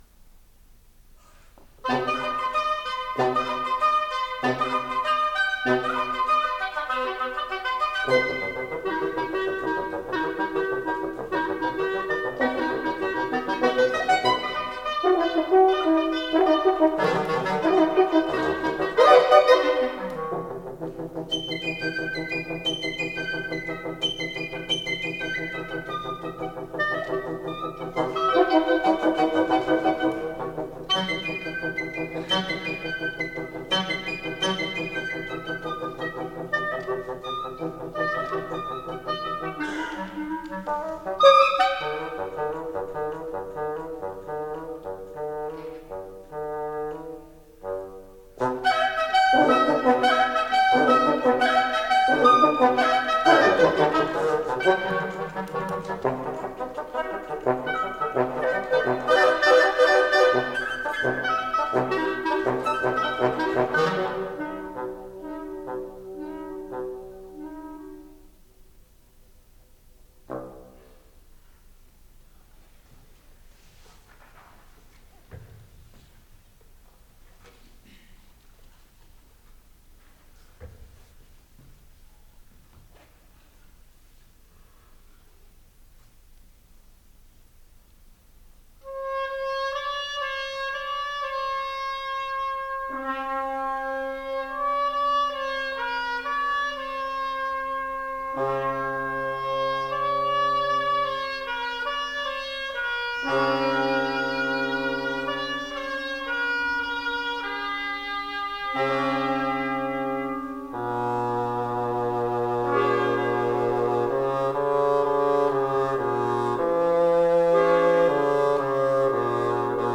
CONCERT OF CHAMBER MUSIC FOR WINDS Monday, March 3, 2003 8:00 p.m. Lillian H. Duncan Recital Hall
PROGRAM: Quintet, Op.79 / August Klughardt -- Six Bagatelles for Wind Quintet / George Ligeti -- Divertissement for Oboe, Clarinet, and Bassoon / Jean Francaix